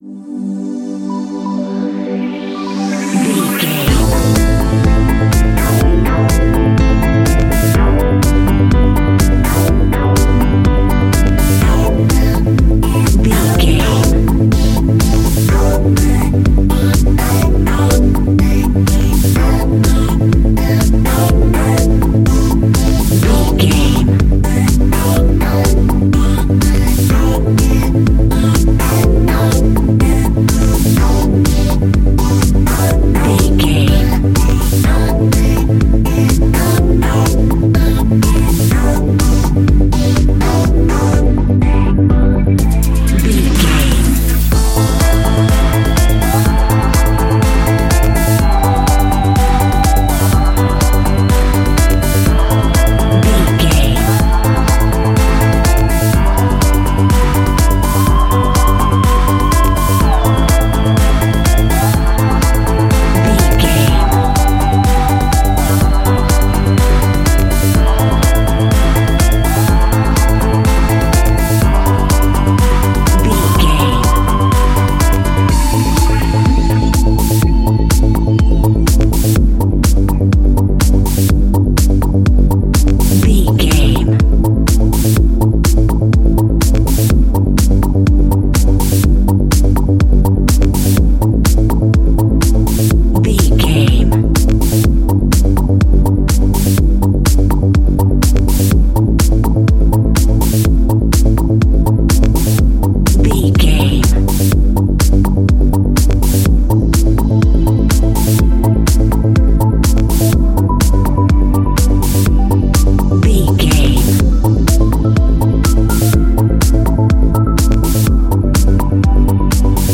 Aeolian/Minor
dark
futuristic
driving
energetic
tension
piano
strings
synthesiser
drums
electronica
synth drums
synth leads
synth bass
synth pad
robotic